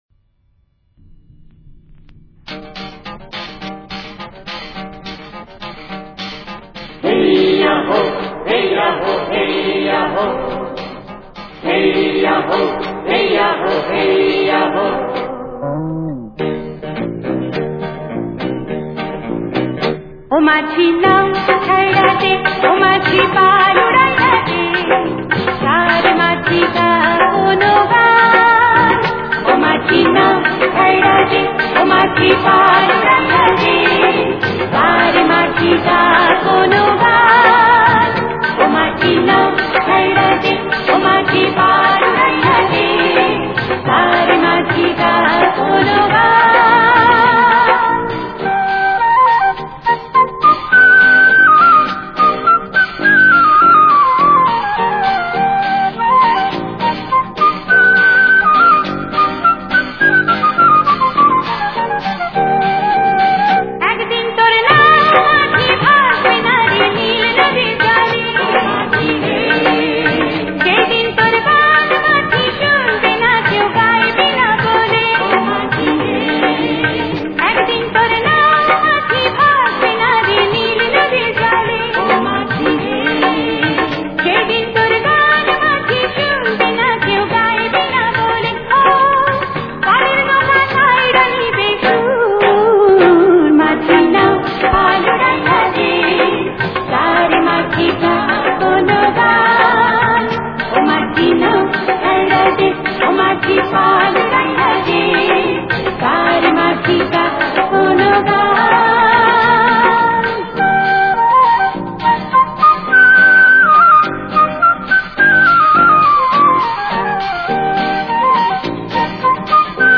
Patriotic Songs